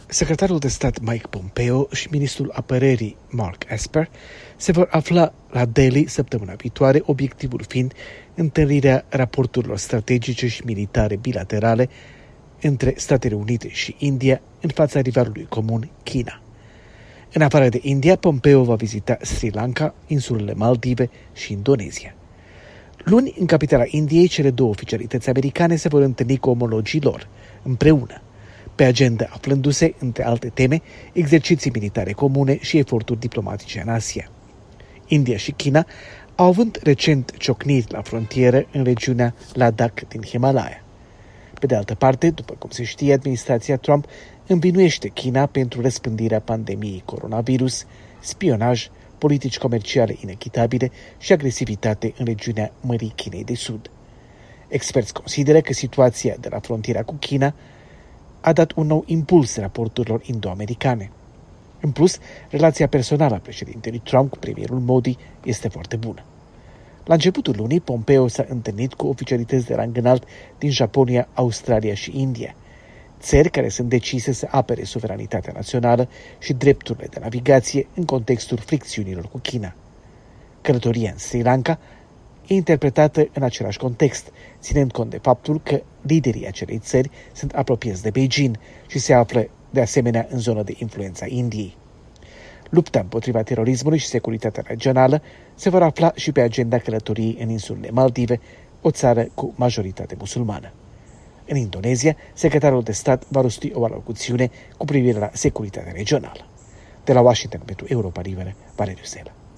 Corespondență de la Washington